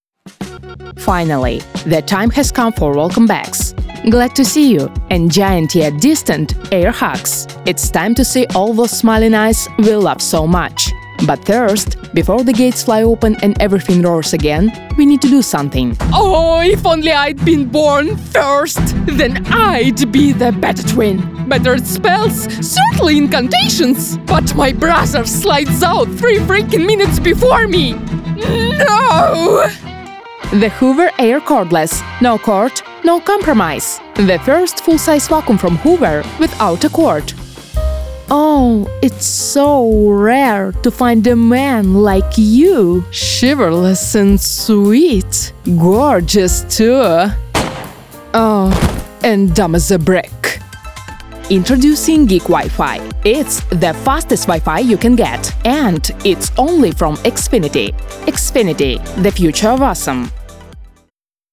Native Ukrainian & Russian Voice Artist | English & French with Slavic Accent
English Multi-Style Reel (Slavic accent)
My delivery ranges from calm, sophisticated narration and warm commercial tones to expressive, high-energy storytelling.